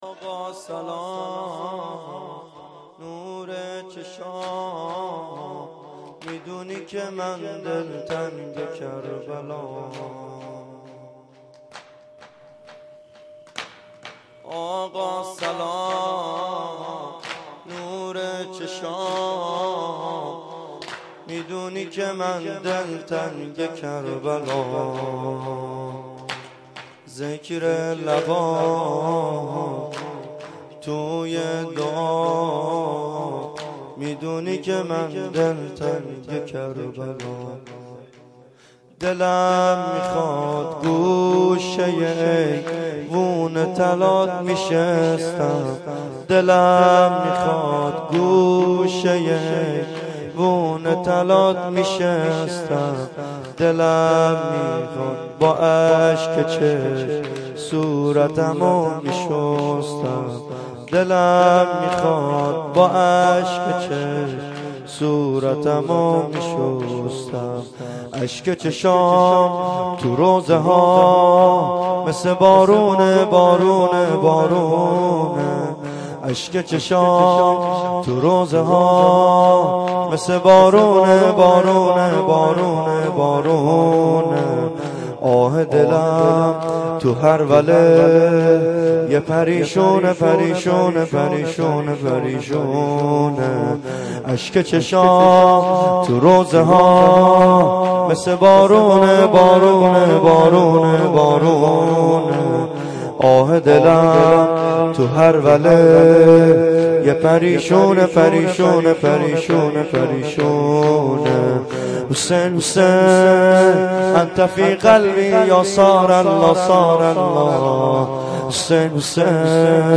سه ضرب
شب سوم اربعین 93.mp3